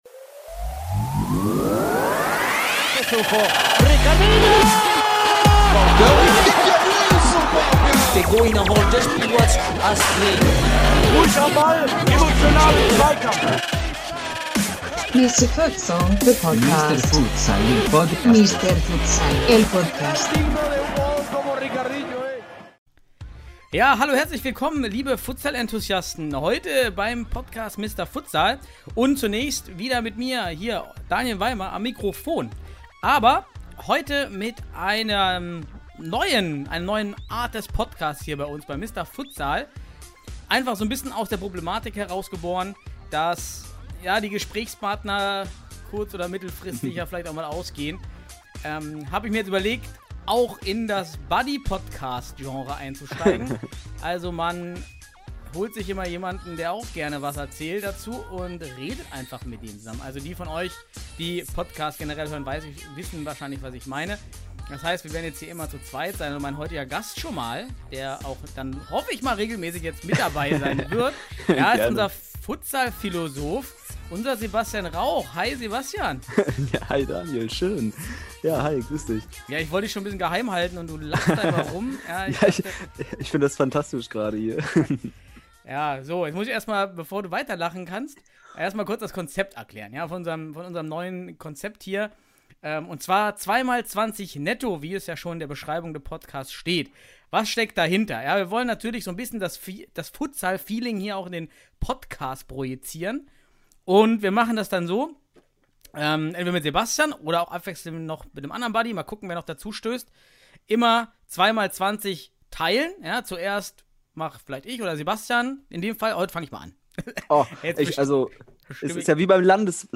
Das Konzept ist einfach: es gibt einen Time-Stopp, 20 Minuten Gesprächsführung durch Buddy 1, Halbzeitpfiff, 20 Minuten Gesprächsführung Buddy 2 und dann Abpfiff nach 40 Minuten netto.